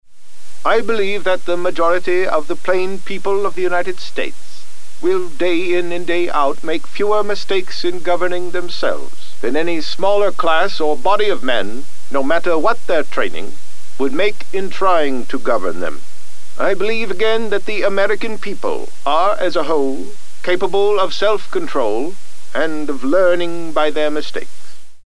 Retro or Historic Vocal Style
Maybe you’re producing a documentary and need specific Theodore Roosevelt quotes or a speech read in his voice, but no recordings of those quotes or speech exists. The good news is that there are recordings of Theodore Roosevelt, and after studying that audio, I can approximate his tone, cadence and speech patterns to provide what you need.
Tags: retro voice over, Ripley voice, Theodore Roosevelt voice